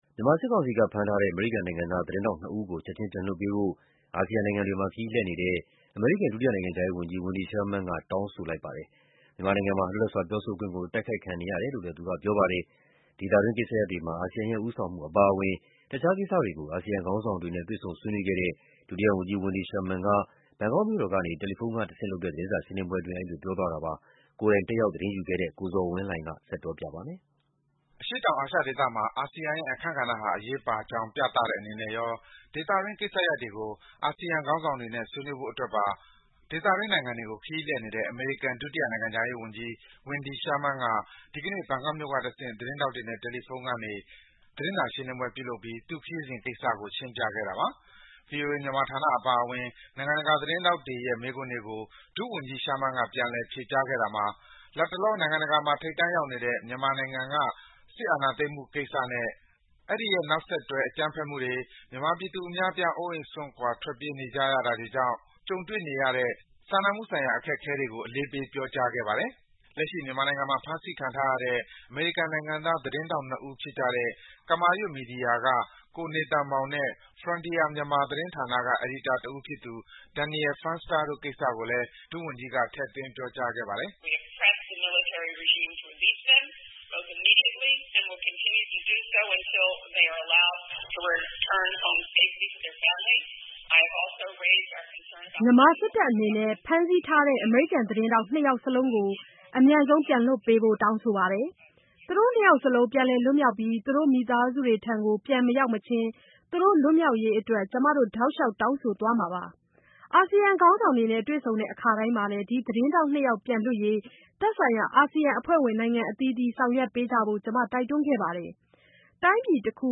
ဒသေတှငျး ကိစ်စရပျတှမှော အာဆီယံရဲ့ ဦးဆောငျမှု အပါအဝငျ တခွားကိစ်စရပျတှကေို အာဆီယံခေါငျးဆောငျတှနေဲ့ တှေ့ဆုံ ဆှေးနှေးခဲ့တဲ့ ဒုတိယဝနျကွီး Wendy Sherman က ဘနျကောကျမွို့ကို ရောကျနစေဉျ တယျလီဖုနျးကတဆင့ျ သတငျးစာရှငျးလငျးပှဲ ပွုလုပျစဉျ အဲဒီလို ပွောကွားလိုကျတာပါ။